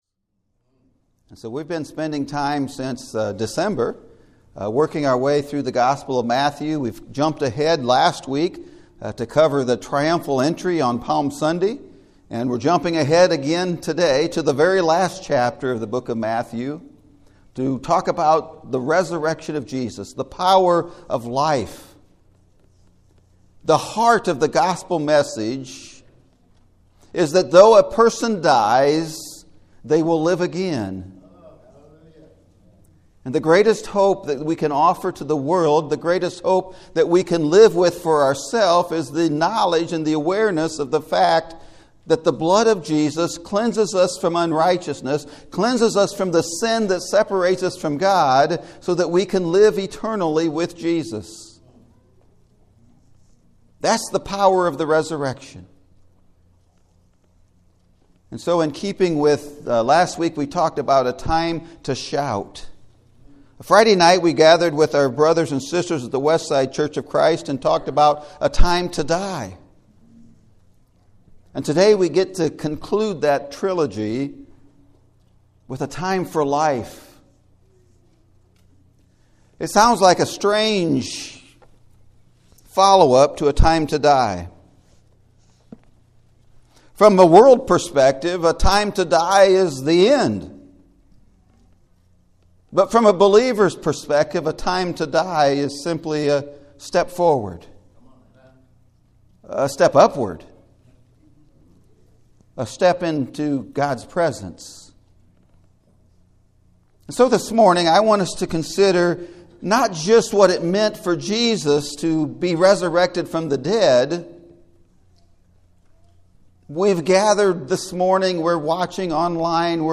Following Jesus – A Time For LIFE! (Sermon Audio)